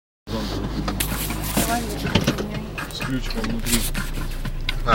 咖啡机 " 牛奶壶
描述：牛奶投手
标签： 浓咖啡 咖啡 酒吧
声道立体声